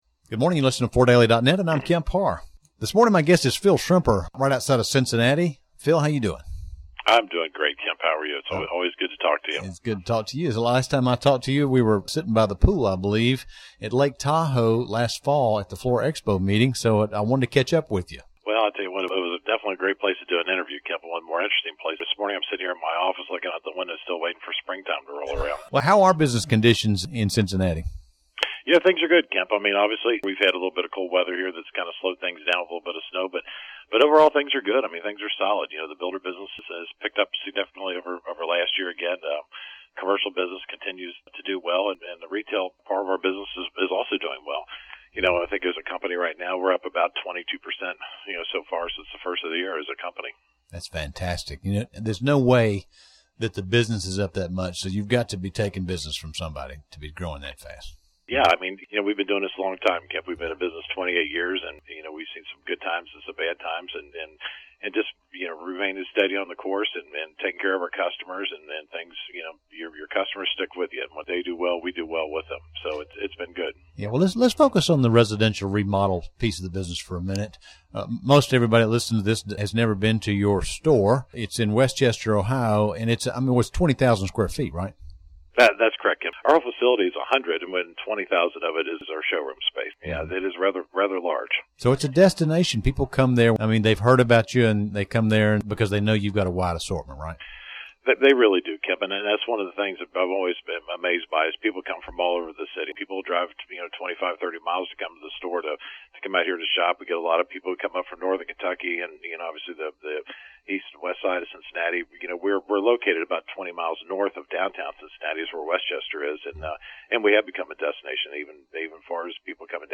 Listen to the interview to hear about this Cincinnati retailer's recent success at the Cincinnati Home and Garden Show, the continued shift to hard surfaces, and how his consumers are buying better end goods.